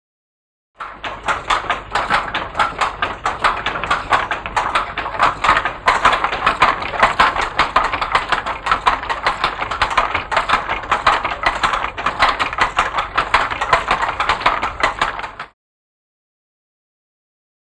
Loom; Hand Loom Running. Shifts And Bangs.